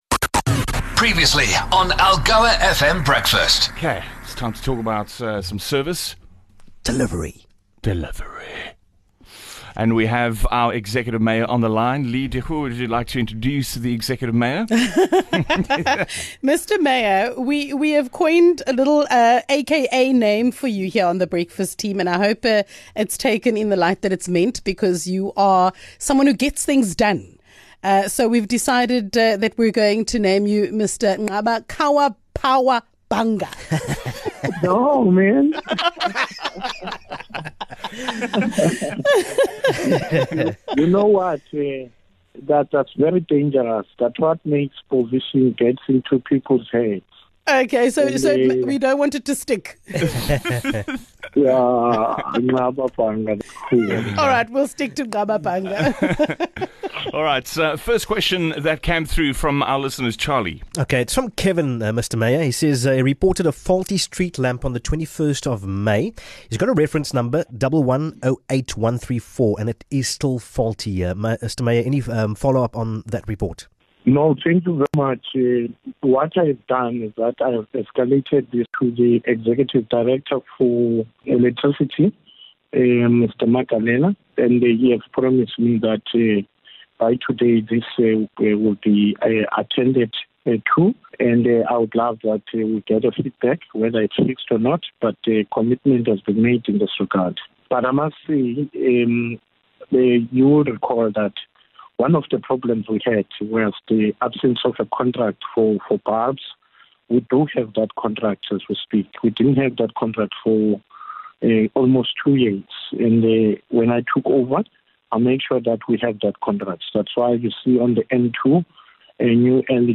Mayor Bhanga was back in the hot seat to answer your service delivery questions. On the agenda - water leaks, street lights, and potholes.